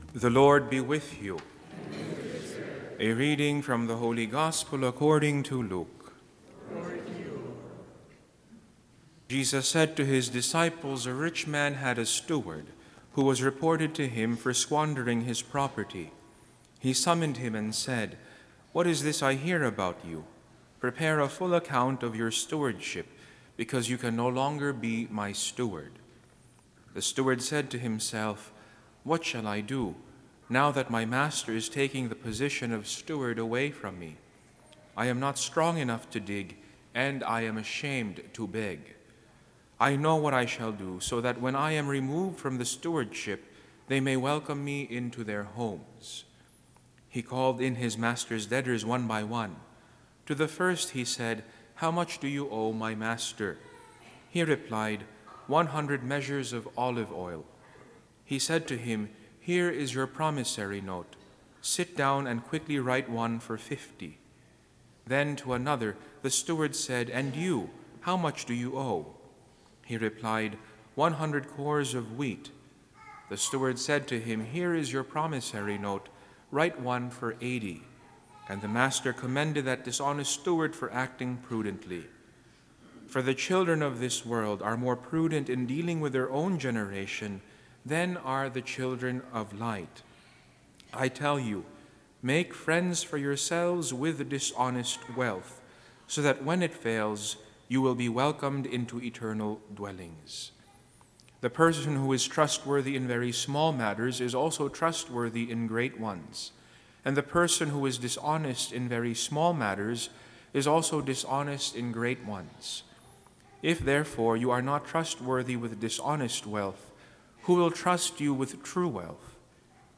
Homily for Sunday September 22, 2019